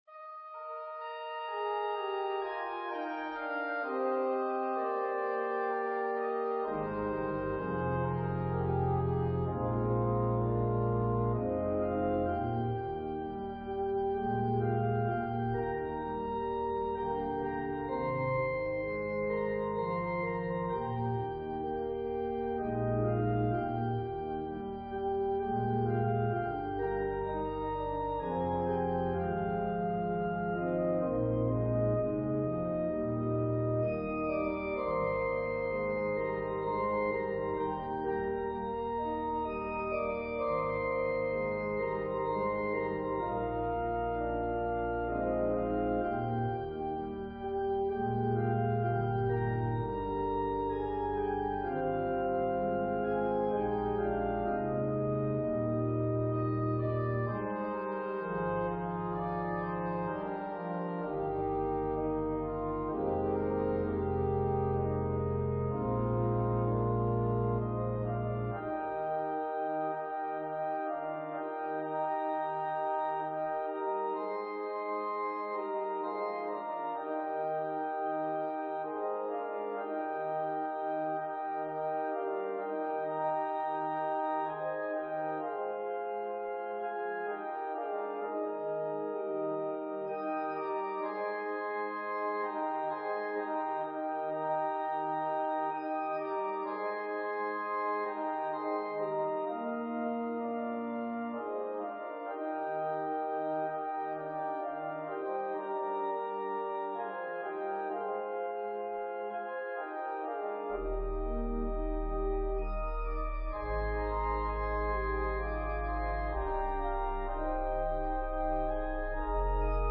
Arranged for organ solo.
Voicing/Instrumentation: Organ/Organ Accompaniment